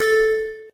Techmino/SFX/blip_1.ogg at 809a6a7600c4ace682b901f50df4080e20a00a40
blip_1.ogg